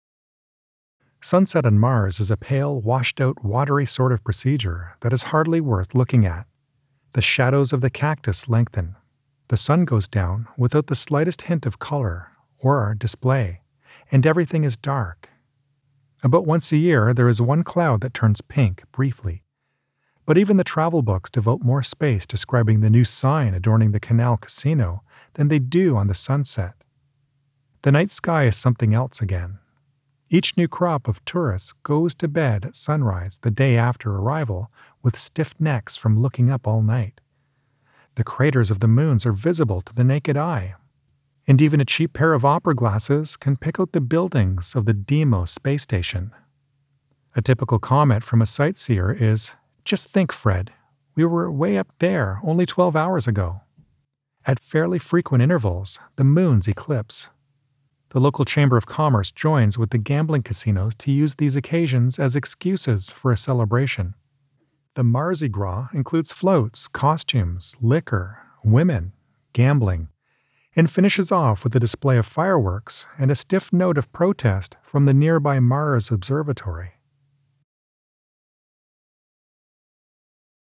An energetic hard sell, a softer delivery or something a little wackier!
English (British) Yng Adult (18-29) | Adult (30-50)